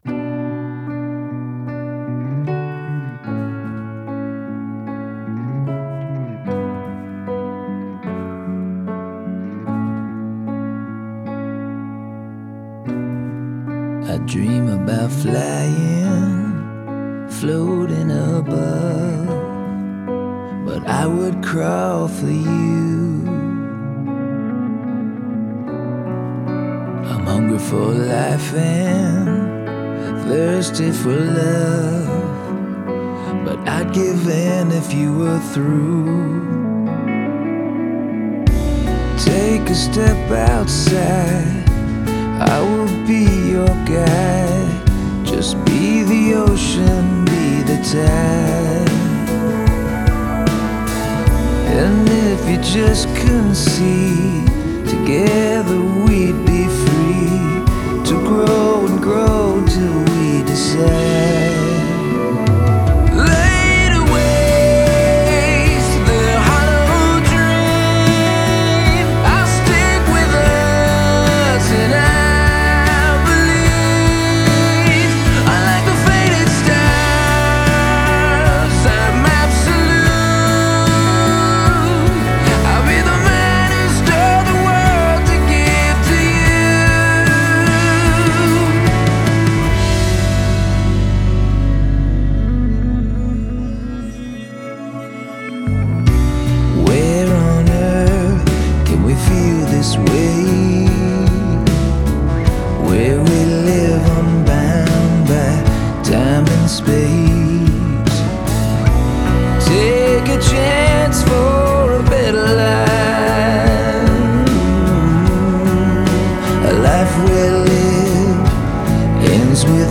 یک گروه راک تازه تاسیس که احتمالا اکثریتتون نشناسین :)